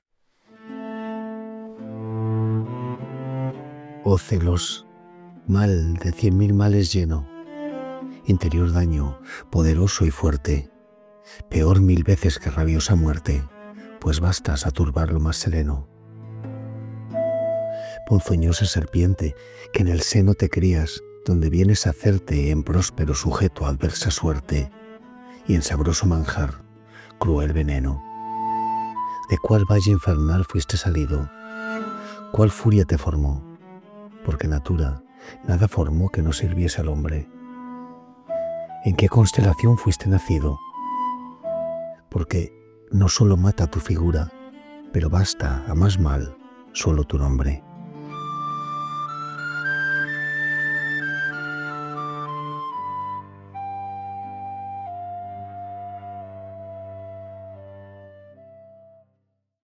Hernando-de-Acuna.-Oh-celos_music.mp3